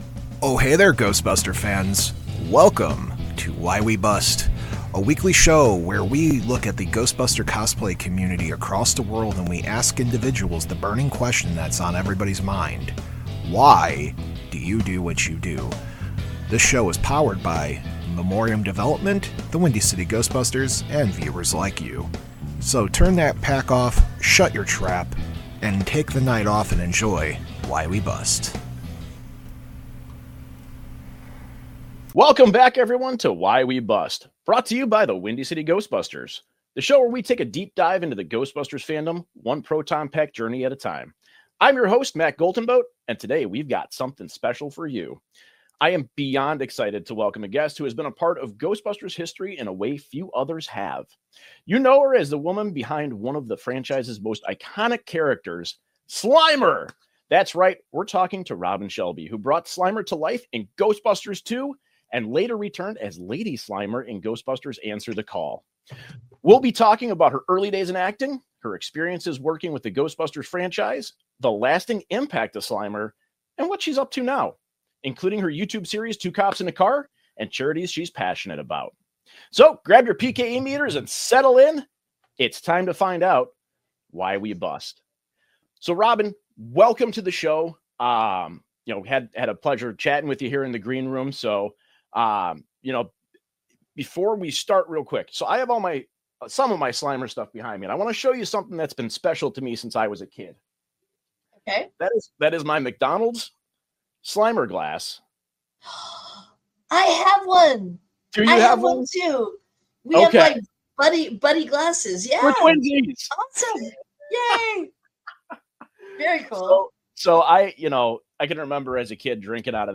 This interview we talk about how Ghostbusters shaped her life, and what sort of impact does the hobby have on her.